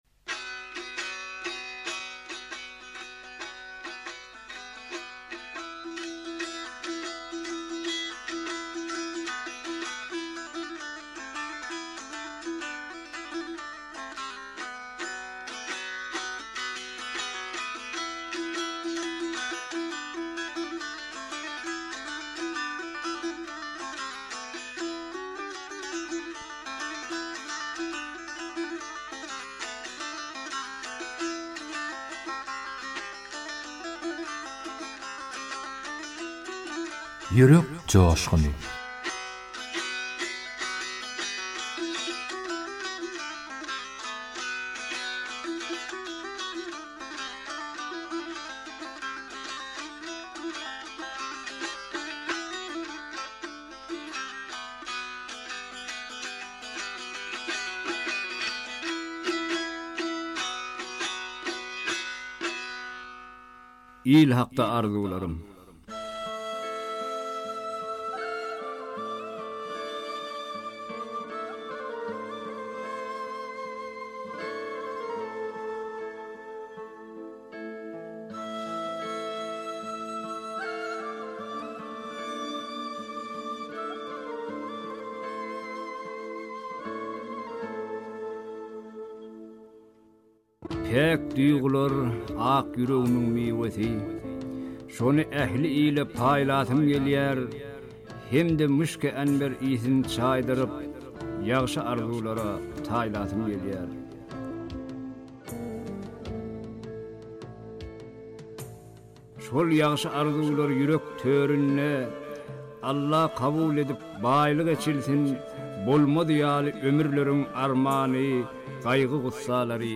goşgy owaz aýdym şygyrlar